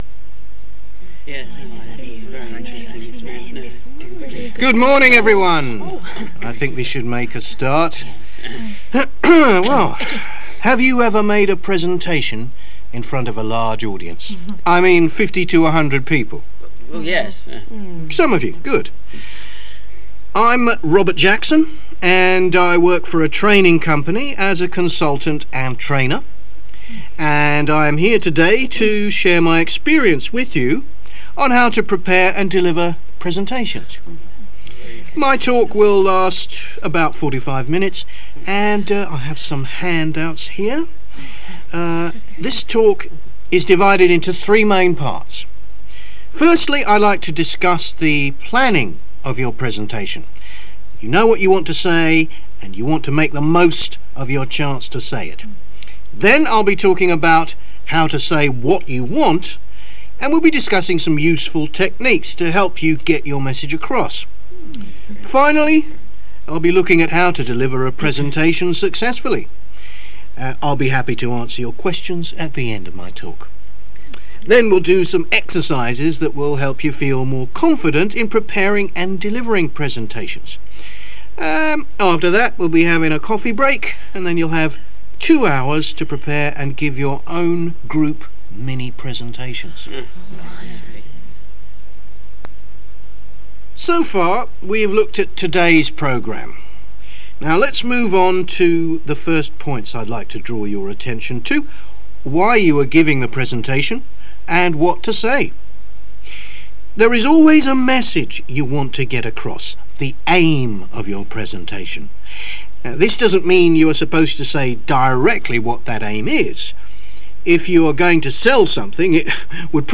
Аудирование. Подготовка и проведение презентации.